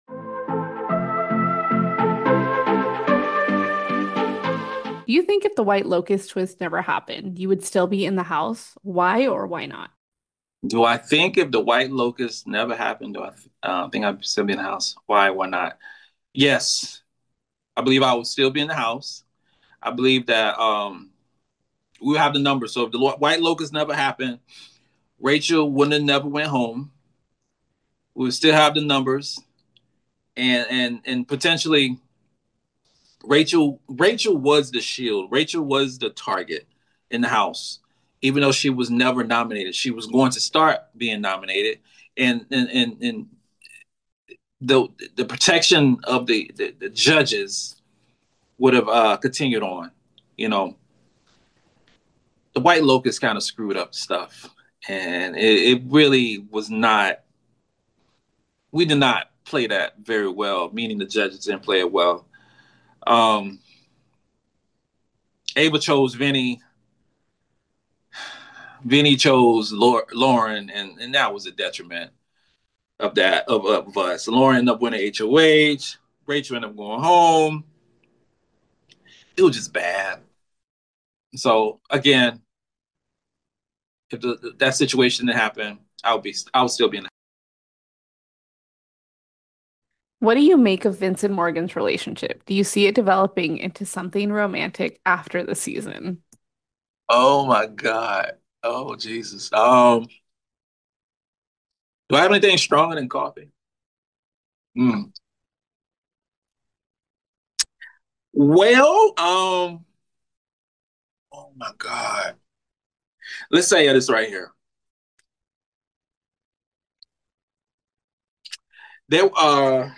Exit Interview